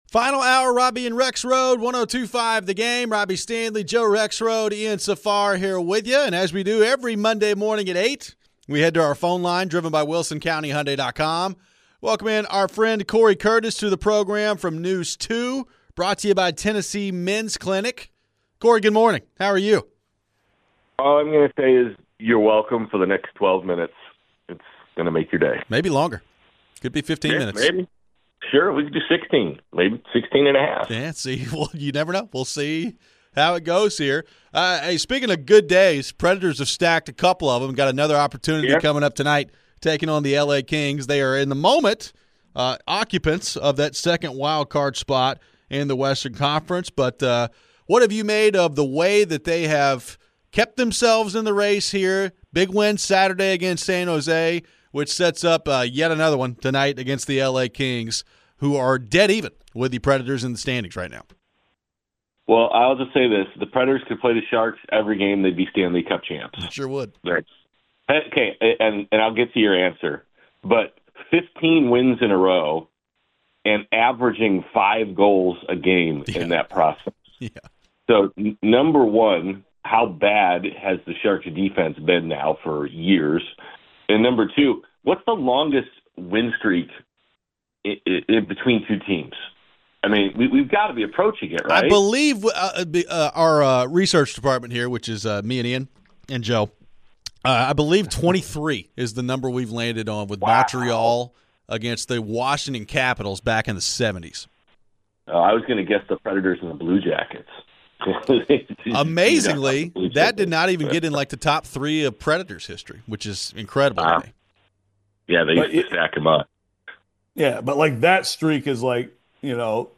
We close out the show with our final Preds thoughts, Men's Title thoughts, and your phones.